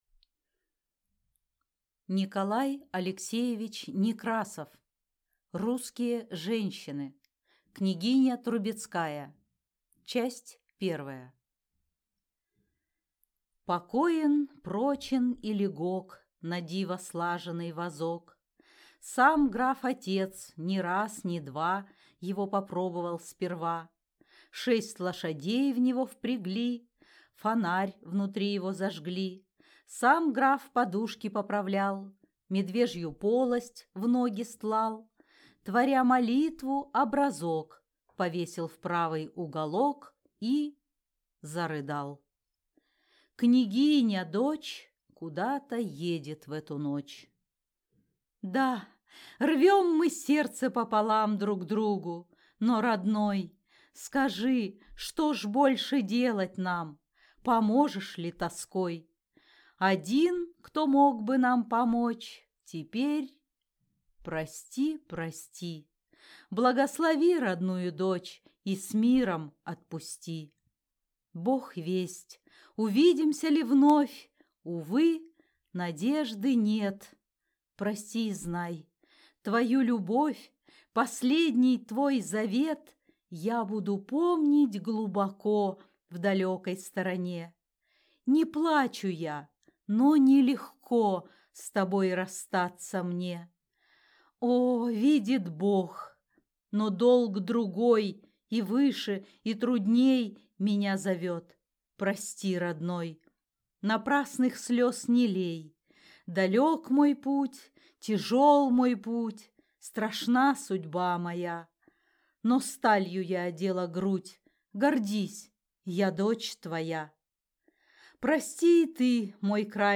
Аудиокнига Русские женщины | Библиотека аудиокниг